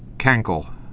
(kăngkəl)